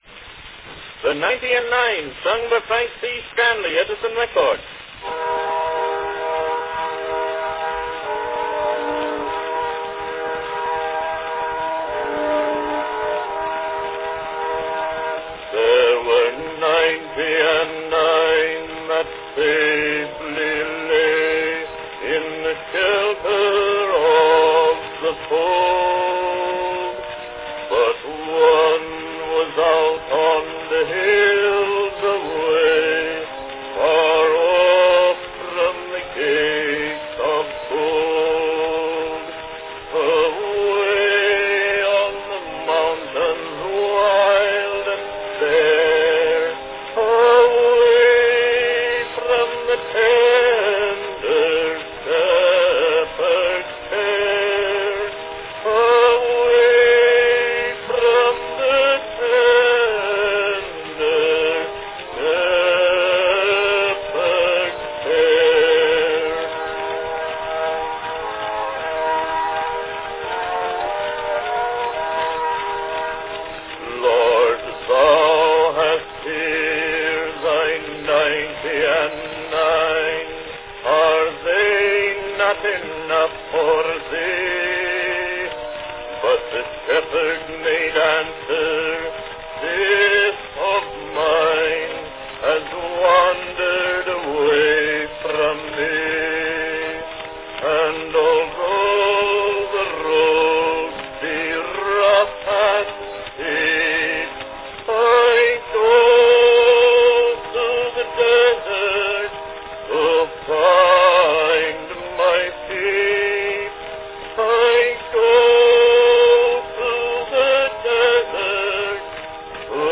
This month:   From 1902, the classic sacred song, The Ninety and Nine, sung by baritone Frank C. Stanley.
Category Baritone
Performed by Frank C. Stanley
Announcement "The Ninety and Nine, sung by Frank C. Stanley.  Edison record."
Recorded frequently throughout the generations and in present times, here we have an early black wax version – although worn down a bit over the years – beautifully rendered by Frank C. Stanley.